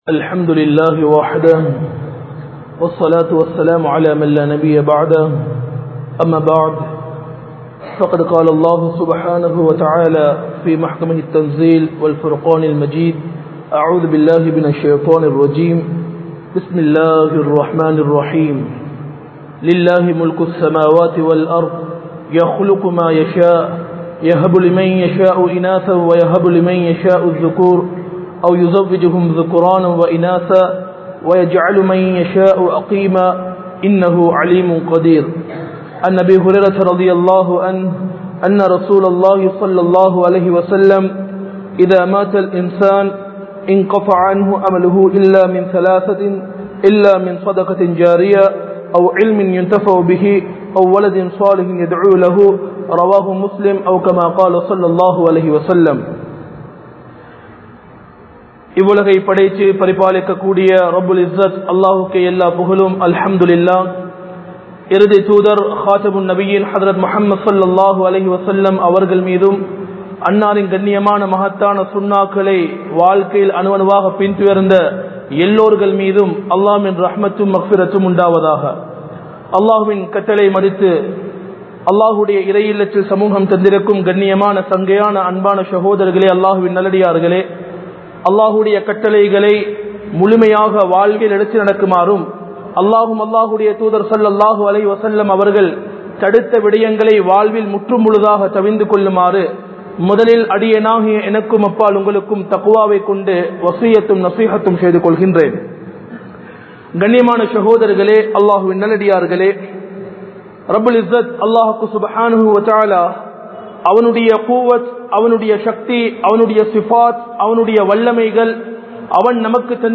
Kulanthaip Paakkiyam (குழந்தைப் பாக்கியம்) | Audio Bayans | All Ceylon Muslim Youth Community | Addalaichenai
Colombo 04, Majma Ul Khairah Jumua Masjith (Nimal Road)